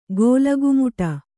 ♪ gōlagumuṭa